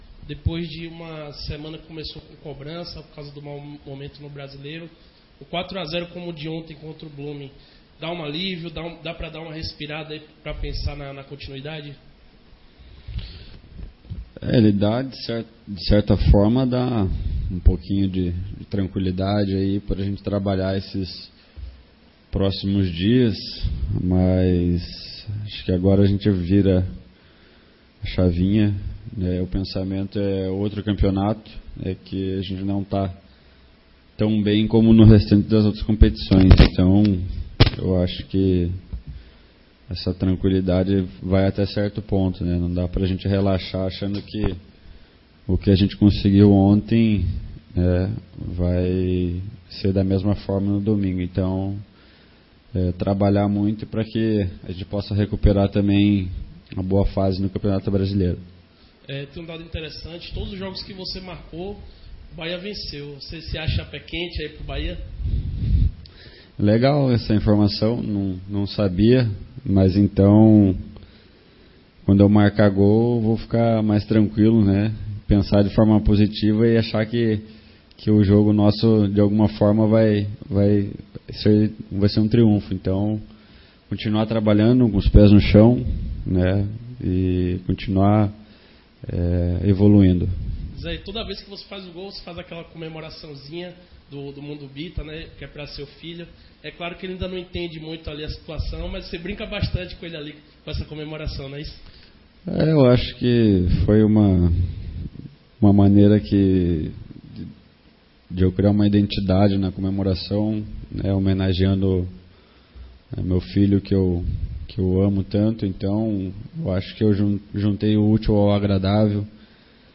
Destaque do Bahia no triunfo de 4 x 0 contra o Blooming-BOL que deu a classificação ao tricolor a vaga para a próxima fase da Copa Sul-Americana, o meia Zé Rafael foi alvo de entrevista nesta quinta-feira no Fazendão. O jogador comentou sobre o resultado e pediu foco na partida de domingo contra o Vasco, pelo Campeonato Brasileiro.